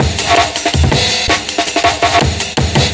8-bit_amen.wav